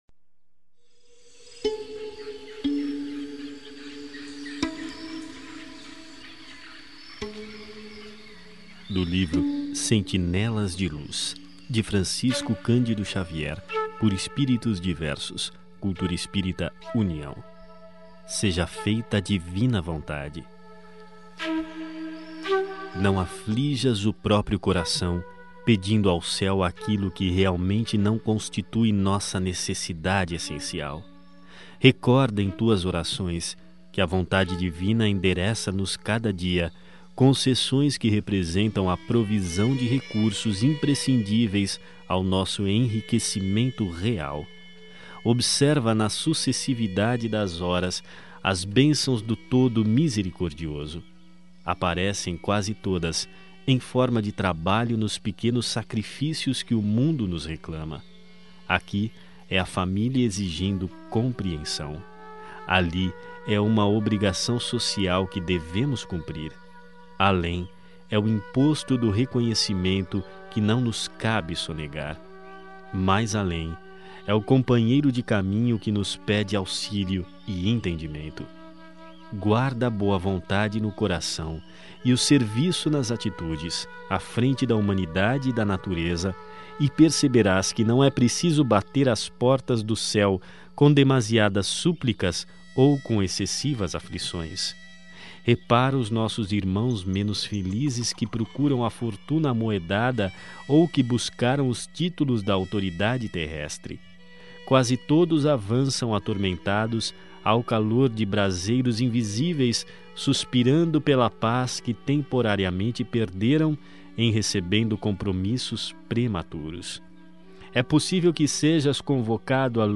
Ouça outras mensagens na voz de Chico Xavier Clicando aqui